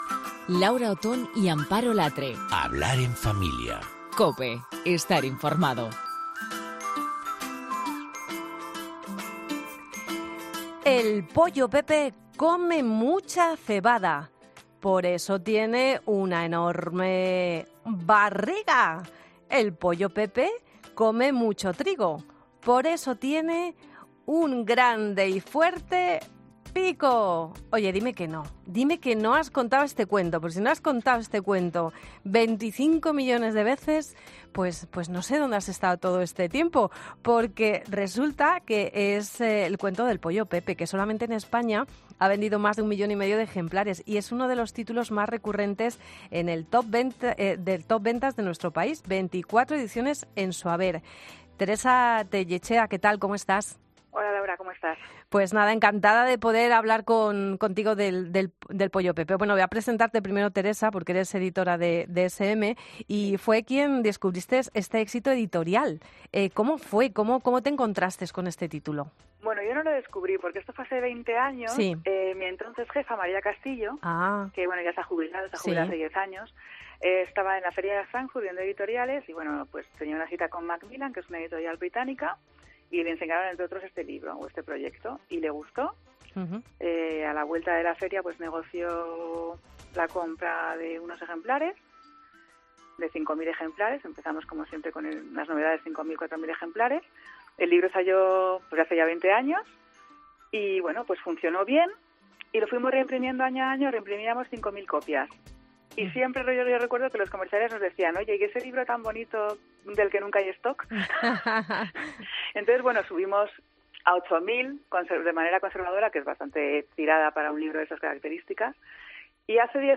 Además te contamos cómo y con qué títulos puedes enganchar a los más pequeños a la lectura. No te pierdas la entrevista.